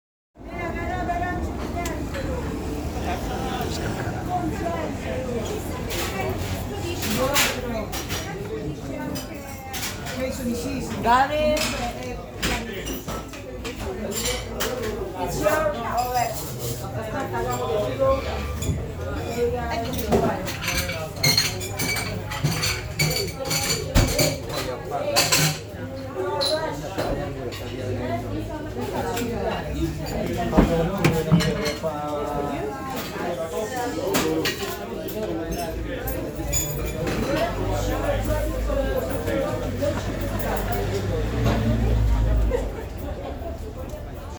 Sounds of Travel
Been reminiscing about those Italian cafes lately. Here’s a photo paired with a recording to transport you.